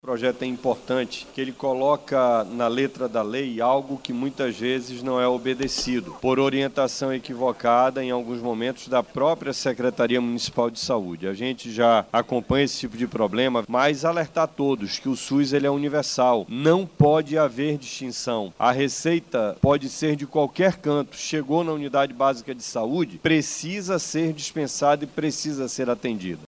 O vereador Marcelo Serafim, do PSB, ressaltou que a medida fortalece a proposta do SUS.